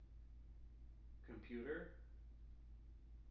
wake-word
tng-computer-382.wav